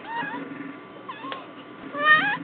She must have had a trauma to her larynx as a kitten, since she sounded like she had permanent laryngitis. A simple "feed me" meow came out like "come quick, Timmy has fallen down the well" - a low-volume but extremely urgent utterance.
[audio] Don't pick me up!
Kit-Kat Mehhh! = "Feed me!" or "Pet me!" or "Don't pick me up!" or "Huh??"